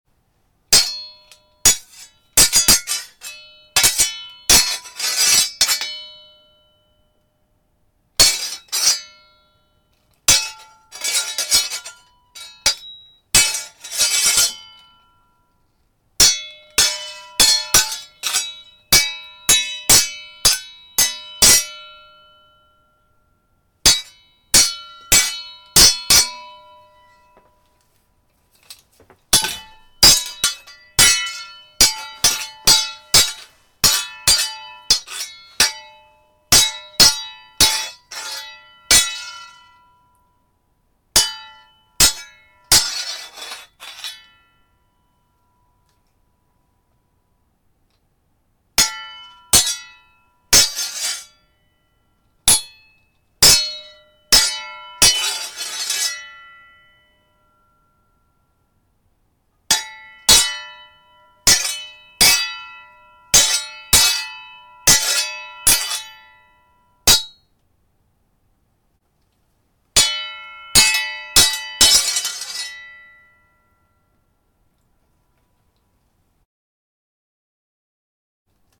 swordfight-1.ogg